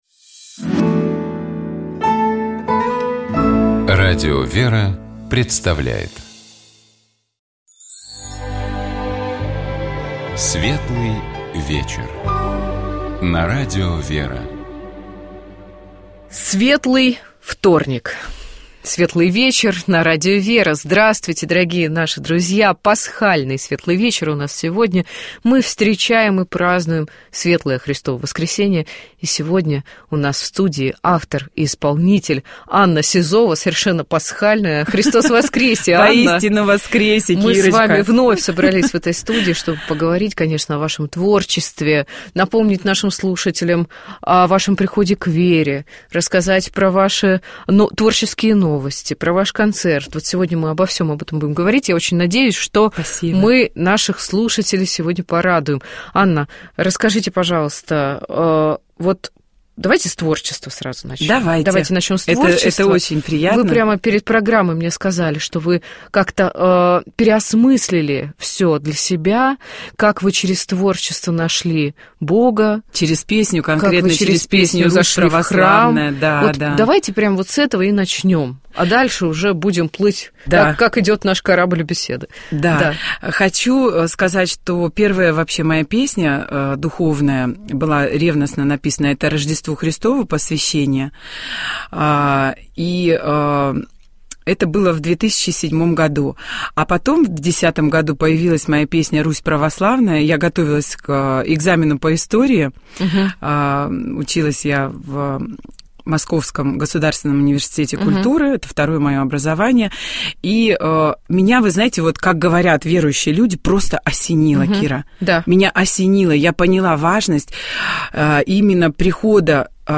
Светлый вечер с Ольгой Васильевой (эфир от 04.12.2014) Нашей гостьей была руководитель Центра Истории религии и Церкви Института российской истории РАН, доктор исторических наук Ольга Васильева.